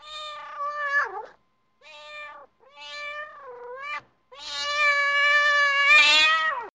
Ringetone Mjauende Kat
Kategori Dyr